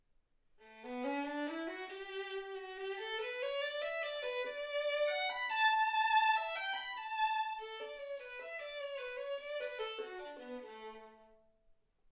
Hegedű etűdök Kategóriák Klasszikus zene Felvétel hossza 00:12 Felvétel dátuma 2025. december 8.